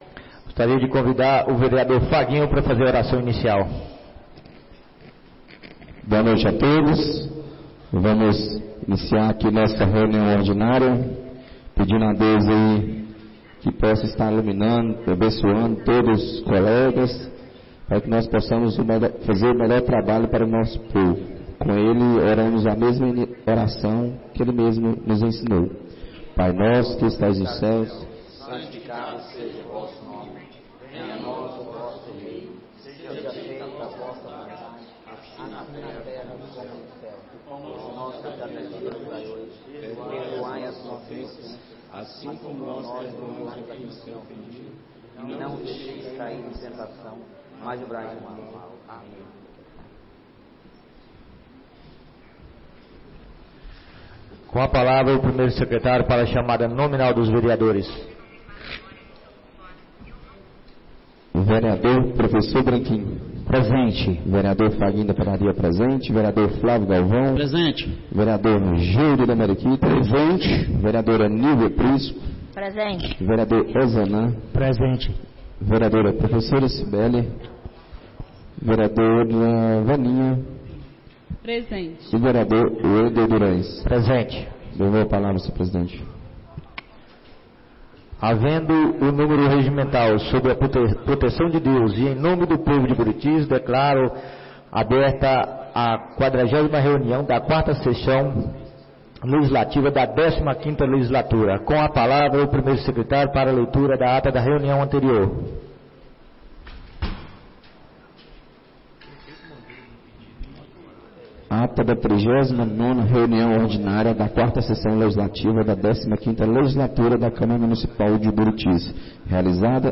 40ª Reunião Ordinária da 4ª Sessão Legislativa da 15ª Legislatura - 09-12-24